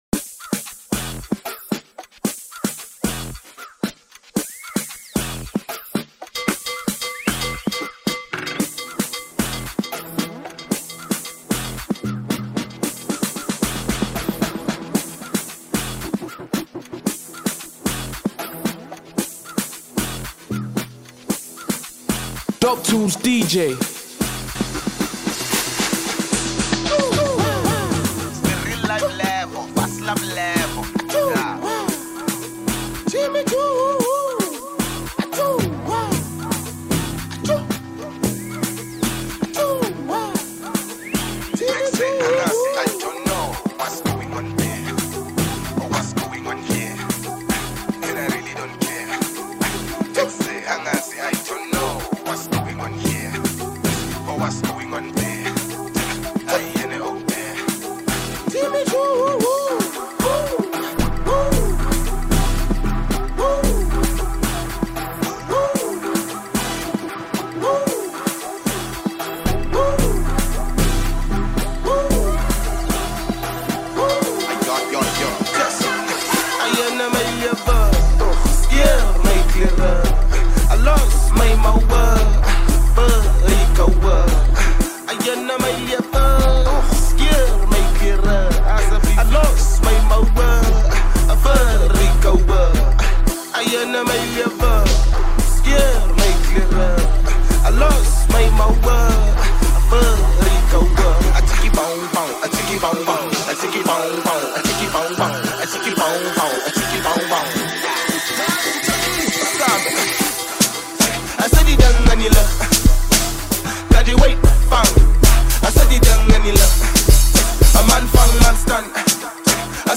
DJ mixtape